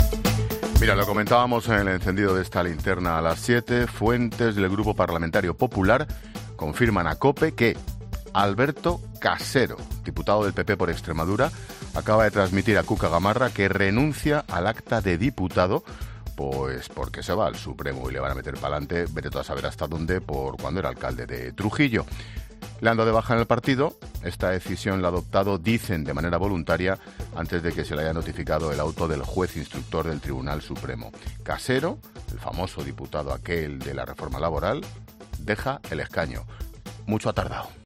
Te da más detalles el director de 'La Linterna', Ángel Expósito